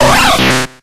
Cries
KABUTOPS.ogg